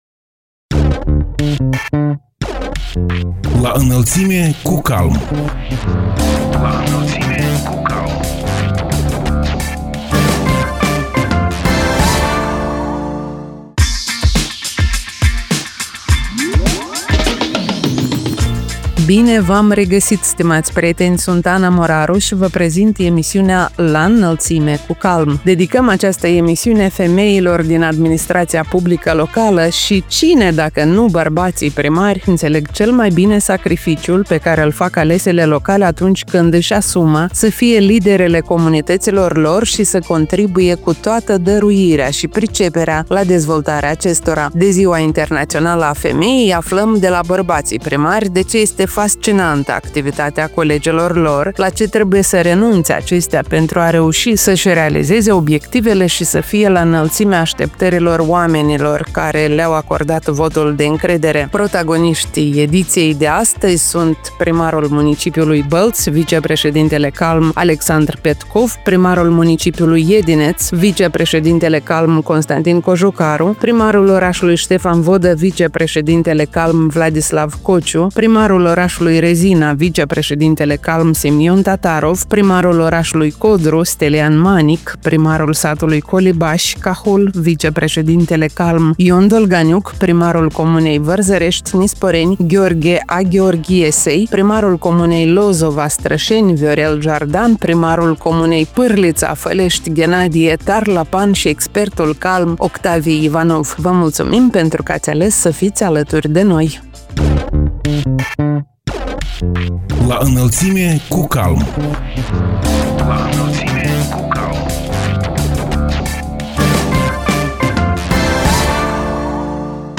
Cine, dacă nu bărbații primari înțeleg cel mai bine sacrificiul pe care îl fac alesele locale atunci când își asumă să fie liderele comunităților lor și să contribuie cu toată dăruirea și priceperea la dezvoltarea acestora? De Ziua Internațională a Femeii, aflăm de la bărbații primari de ce este fascinantă activitatea colegelor lor, la ce trebuie să renunțe acestea pentru a reuși să-și realizeze obiectivele și să fie la înălțimea așteptărilor oamenilor care le-au acordat votul de încredere.